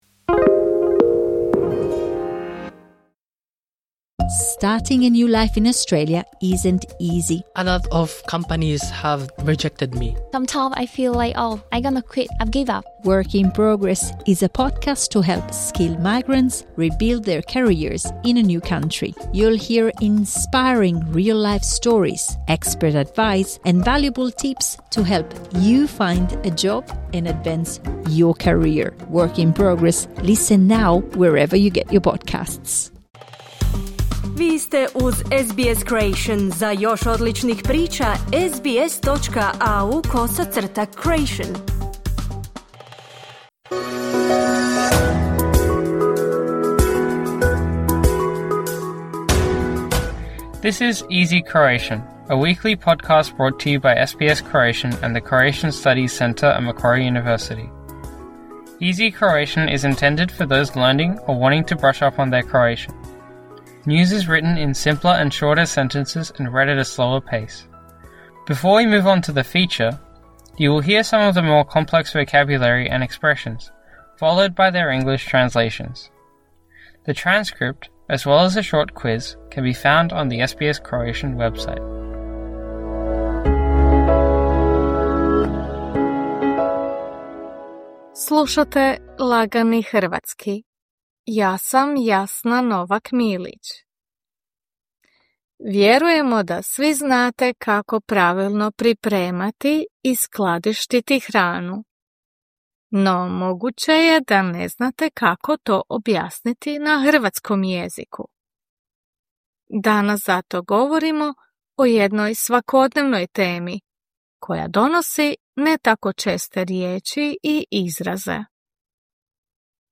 This is „Easy Croatian“ – a weekly podcast brought to you by SBS Croatian and the Croatian Studies Centre at Macquarie University. “Easy Croatian” is intended for those who want to brush up on their Croatian. News is written in simpler and shorter sentences and read at a slower pace.